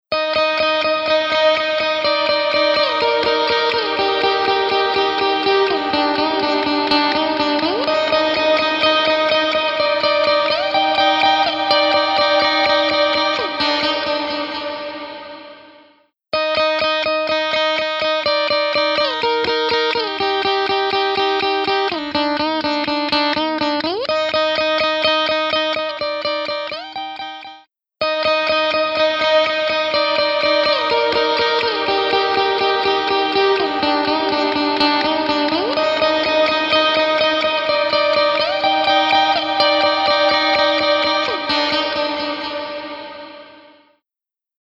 Massive Otherworldly Reverb
Blackhole | Electric Guitar | Preset: H8000 Blackhole
Blackhole-Eventide-Clean-Guitar-H8000-Blackhole.mp3